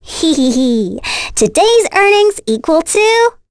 Requina-Vox_Victory.wav